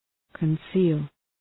Προφορά
{kən’si:l}